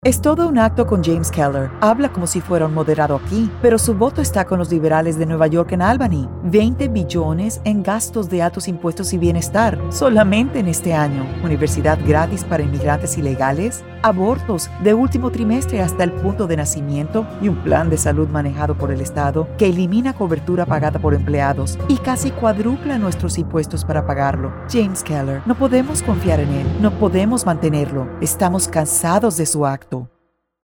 Female Spanish Republican Political Voiceover
Spanish, negative Ad